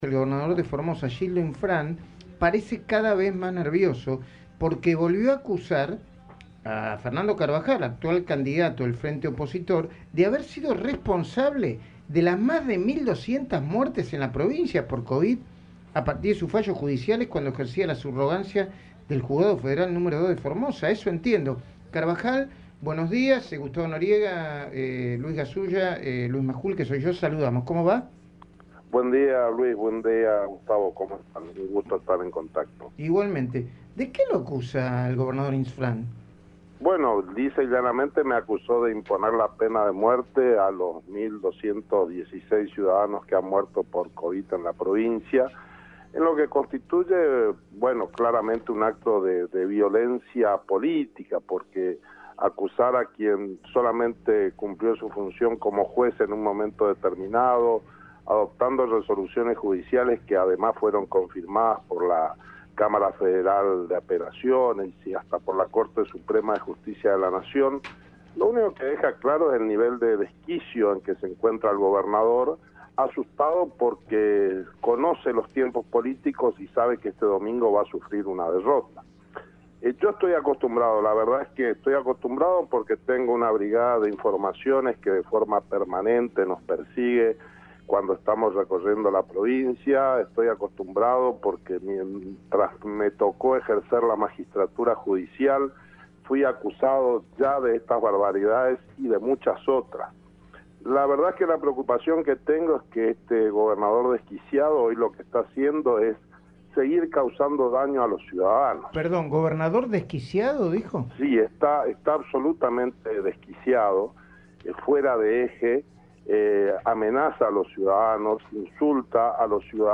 El candidato a diputado nacional conversó con Luis Majul sobre las elecciones del domingo y adelantó que "Insfrán conoce los tiempos políticos y sabe que va a sufrir una derrota".